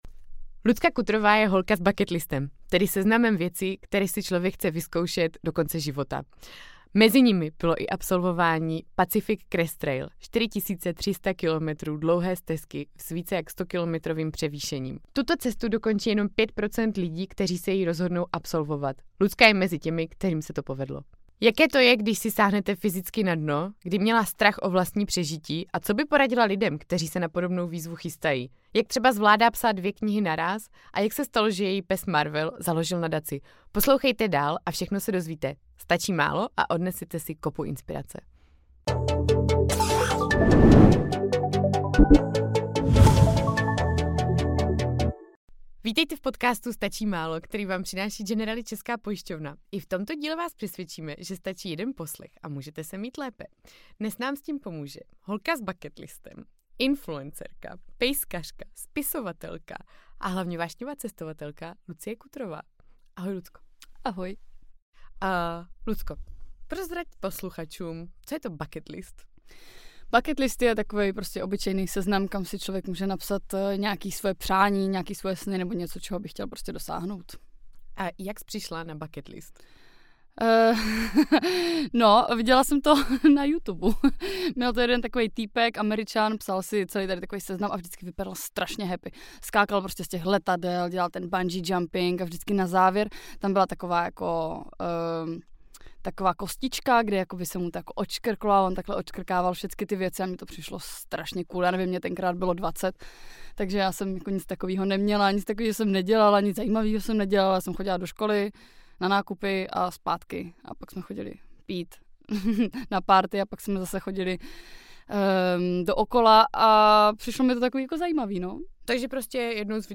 V rozhovoru vám povypráví, jak jí tento zážitek změnil život a proč nikdy nebyla šťastnější než teď. Barvitě vám popíše, jakou největší krizi si na stezce zažila a co nečekaného ji způsobilo.